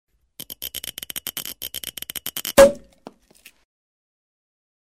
Звук пробки шампанського